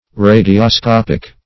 radioscopic.mp3